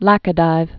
(lăkə-dīv, läkə-dēv)